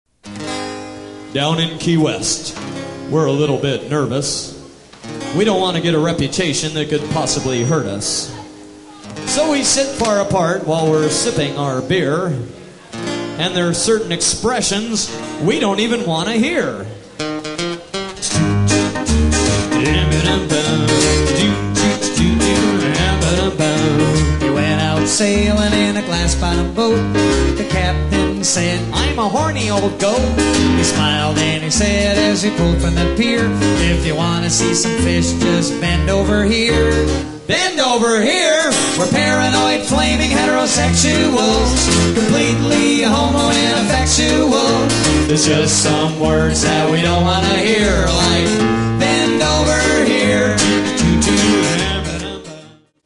DIGITALLY RE-MASTERED FOR BETTER SOUND!